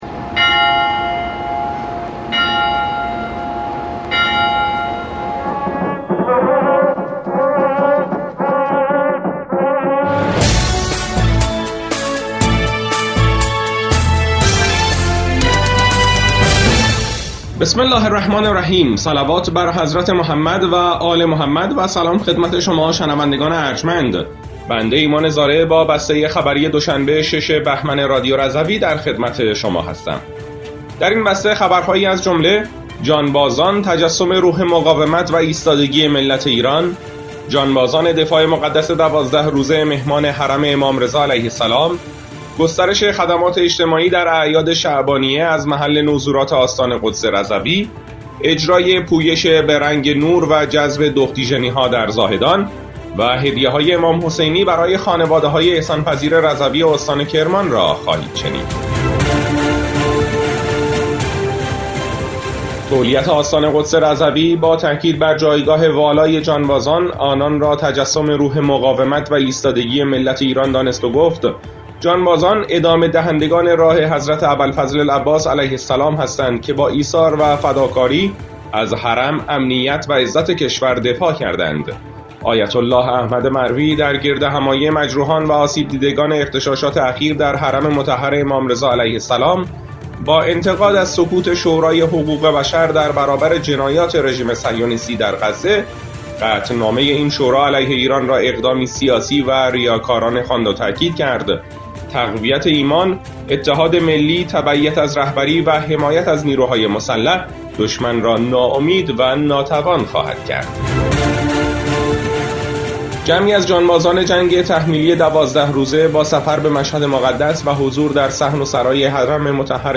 بسته خبری ۶ بهمن ۱۴۰۴ رادیو رضوی؛